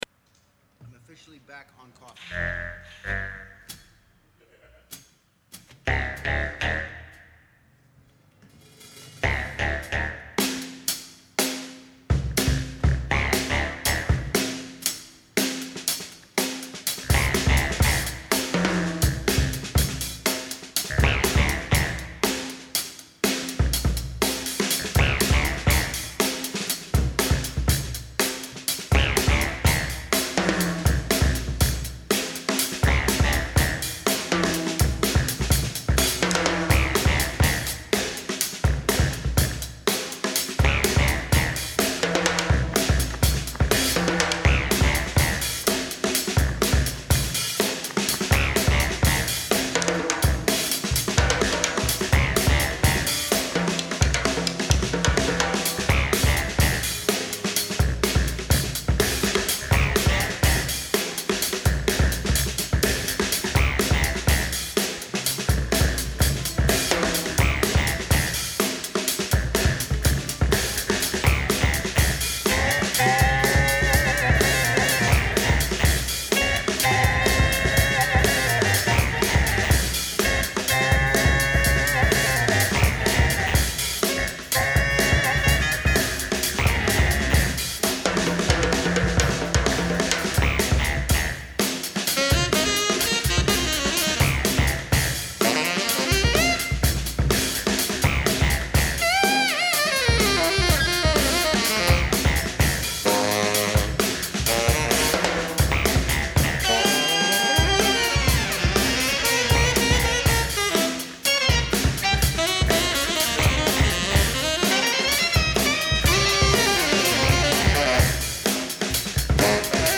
Recorded live at the 39th Street loft in Brooklyn
drums
alto saxophone, fx
tenor saxophone
Stereo (Metric Halo / Pro Tools)